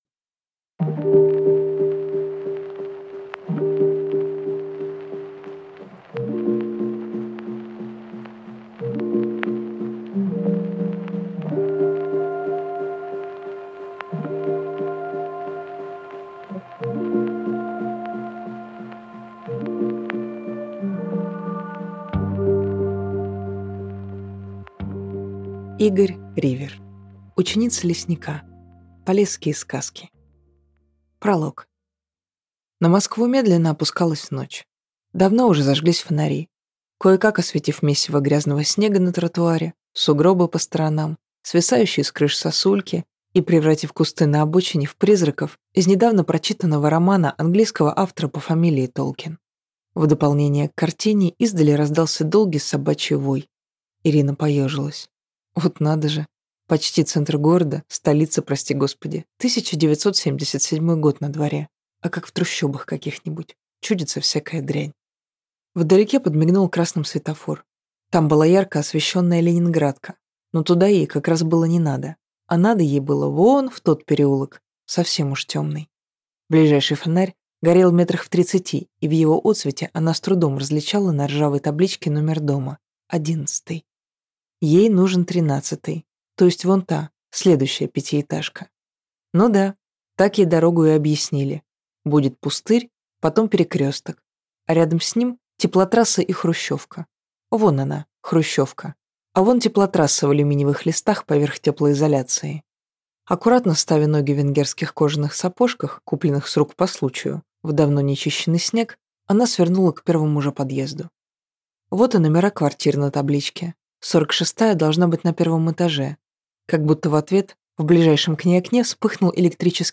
Аудиокнига Ученица Лесника. Полесские сказки | Библиотека аудиокниг